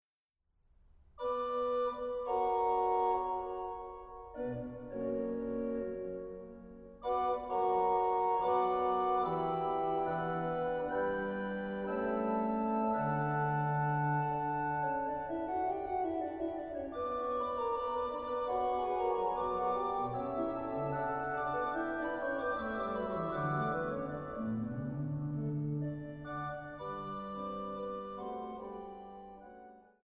Stellwagen-Orgel